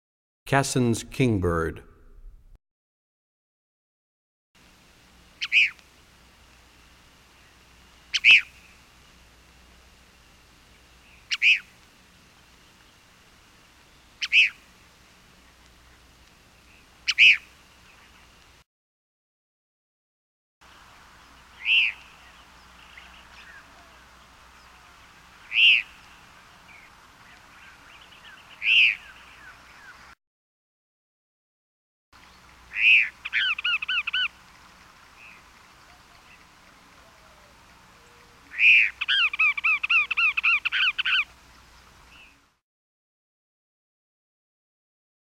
23 Cassin's Kingbird.mp3